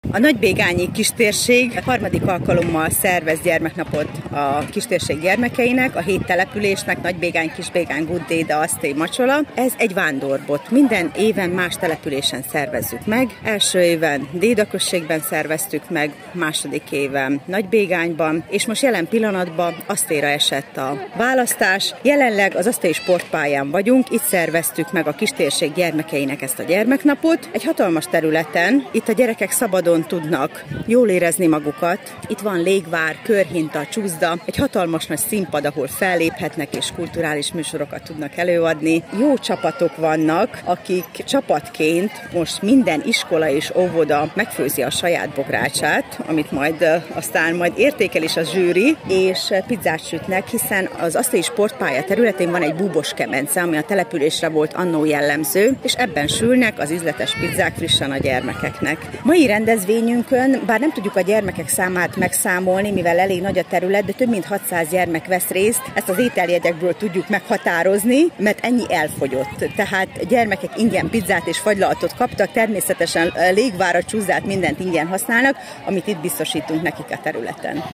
riport_asttely.mp3